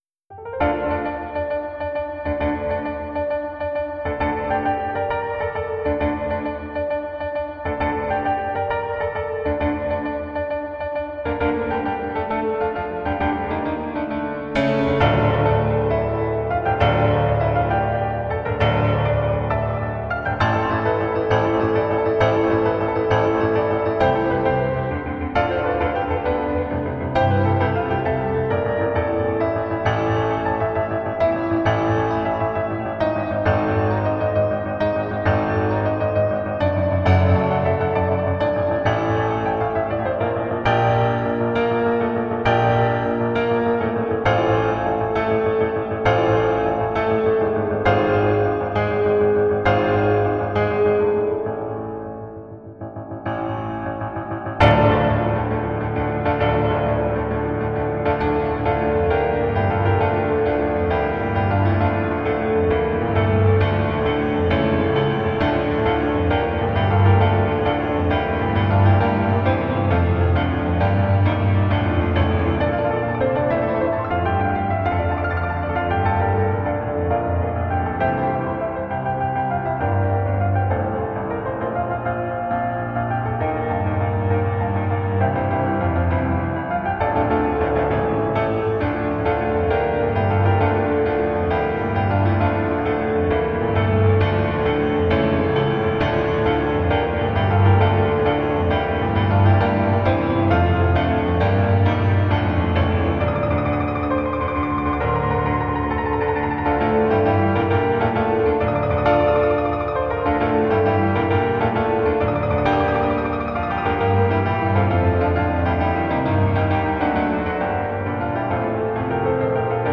And we close playing our two styles going at it.